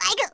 Luna-vox-get_04_kr.wav